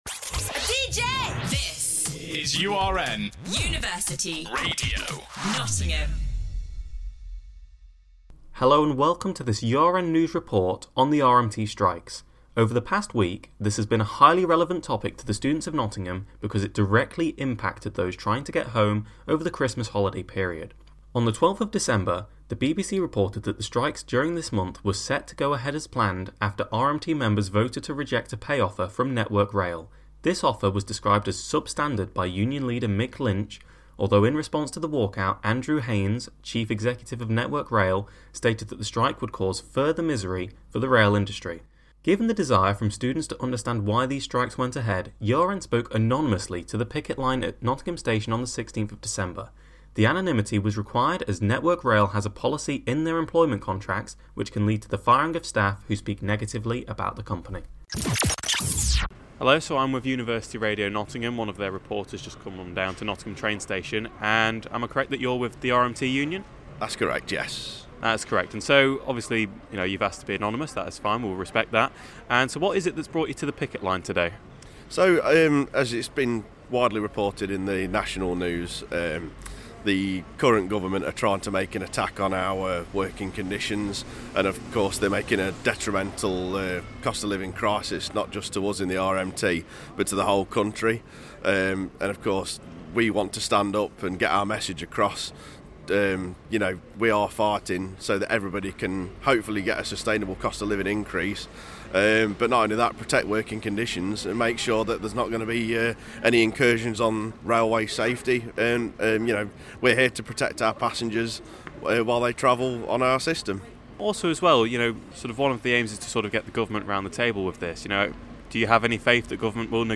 In this report by the URN News Team, we discuss the strike action taken by the RMT Union over December, and interview the picket line at Nottingham station about the impact this is having on students.